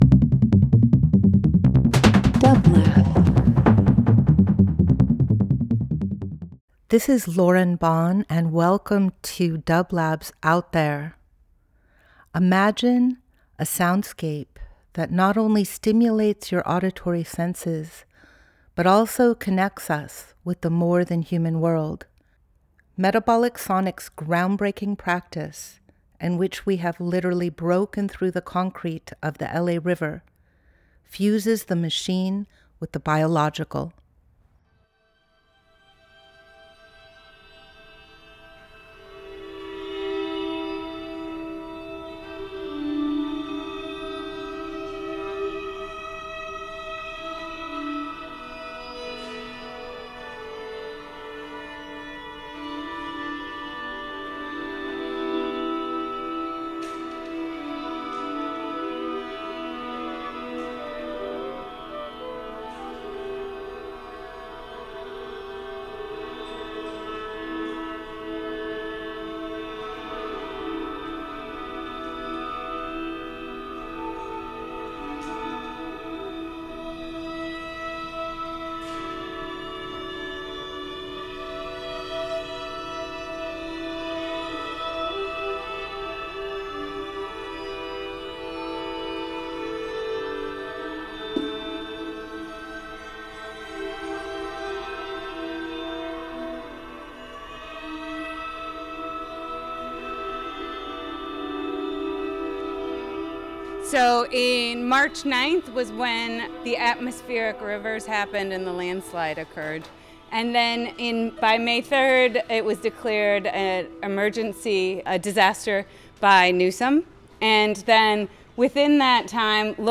Each week we present a long-form field recording that will transport you through the power of sound.
Discussions on the Moon, 8-1-25 Part One: This is part of a community discussion about Metabolic Studio’s project Moving Mountains, which redistributes healthy topsoil from landslides in the art form Meandros. This discussion took place on August 1, 2025 at a tour of “The Moon”, a property that has been un-developed and is supporting native plant re-growth. The discussion is interspersed with improvised music by Metabolic Sonics.